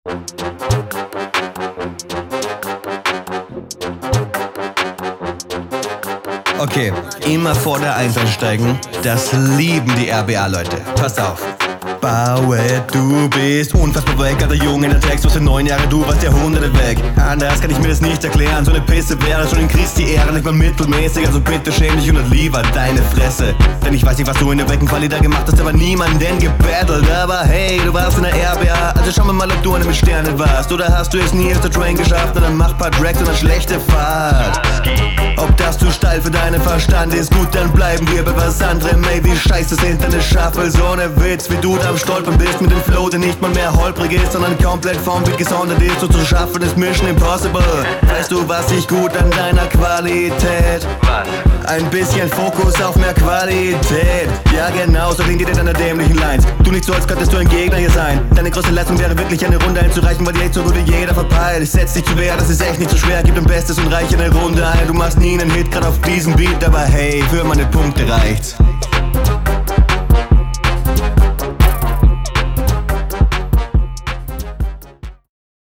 Was mir gut gefällt, ist das Timing beim Flow und ein paar Reimketten, die ganz …